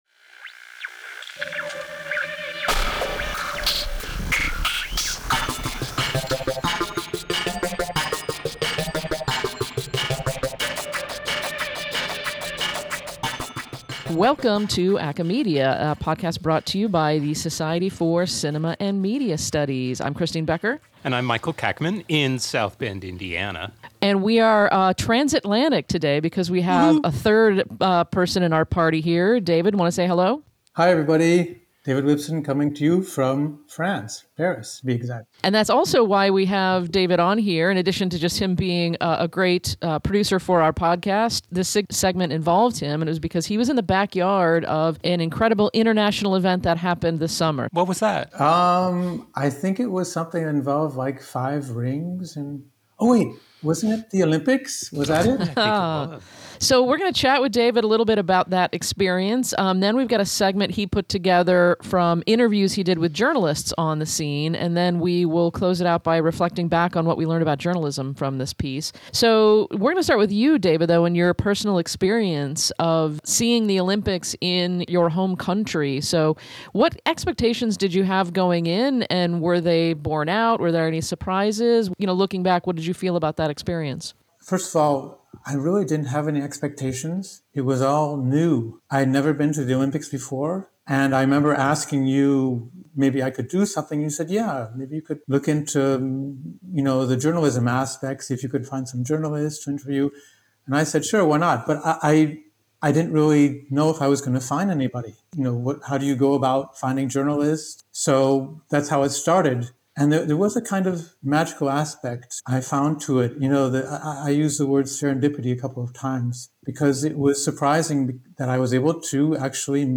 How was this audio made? He captured some sounds of the city and the people flocking to arenas and also turned modern journalism’s most essential tool – a smartphone – toward journalists themselves, uncovering